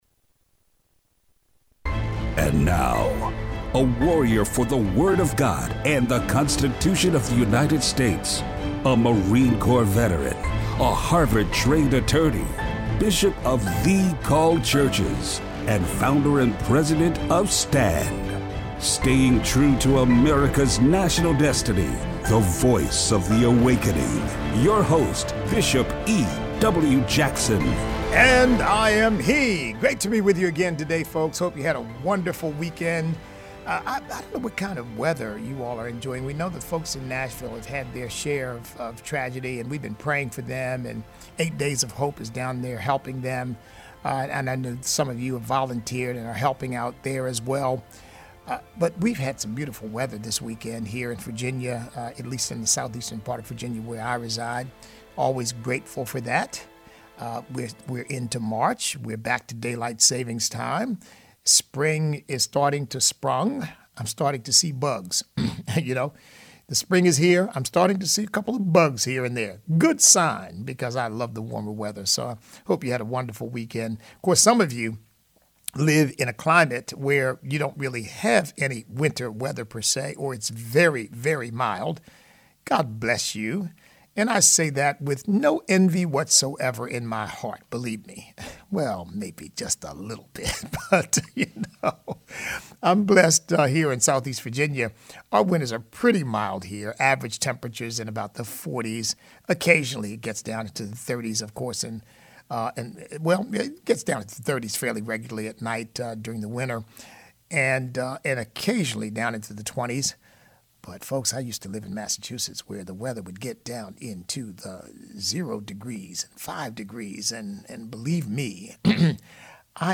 As the world goes into panic mode over the Coronavirus, Christians can remain calm and at peace. Listener call-in.